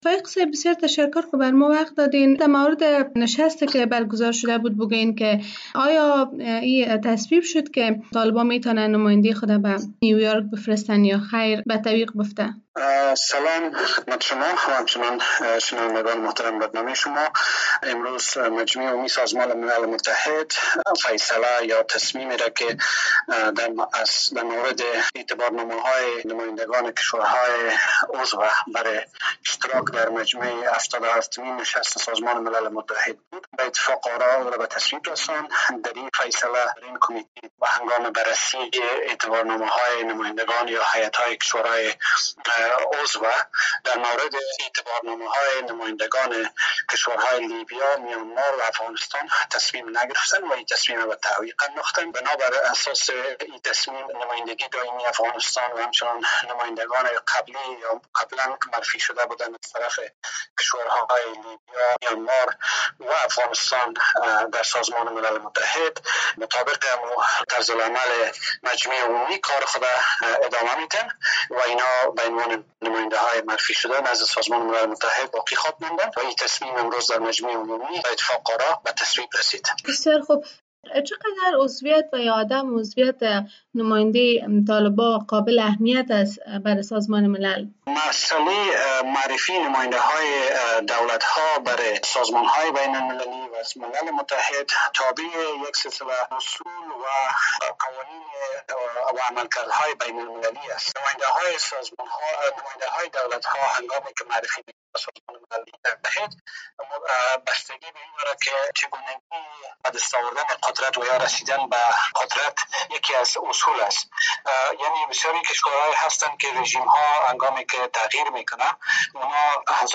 نصیر احمد فایق نمایندهٔ فعلی افغانستان برای کرسی دایمی سازمان ملل متحد می‌گوید به عنوان نمایندهٔ فعلی افغانستان در سازمان ملل به کارش ادامه خواهد داد. آقای فایق در صحبت اختصاصی با رادیو آزادی افزود، از آن‌جای که حکومت طالبان به رسمیت شناخته نشده، پذیرفتن نمایندهٔ آن‌ها از سوی این سازمان کار...